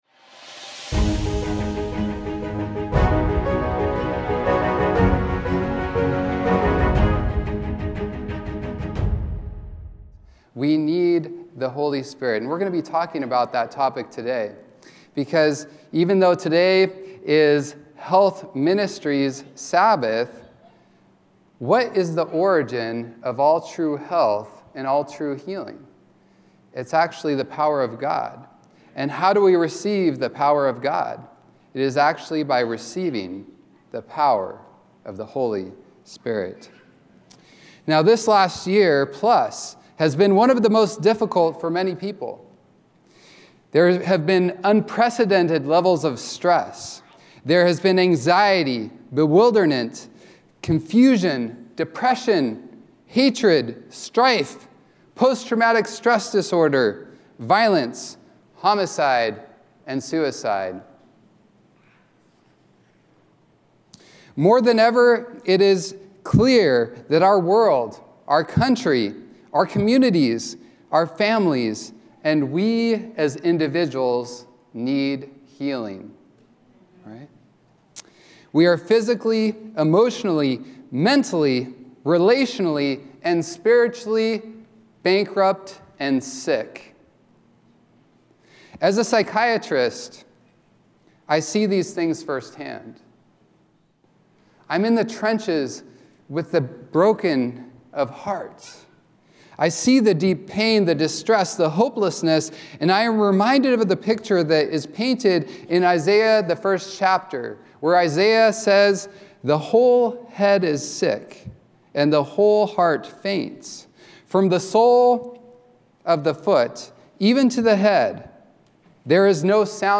A message from the series "Central Sermons."